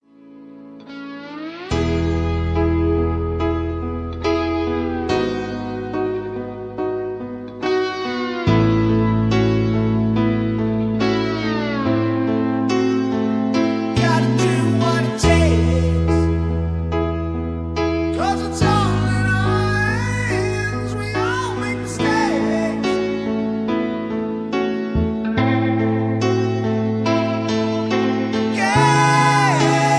backing tracks, rock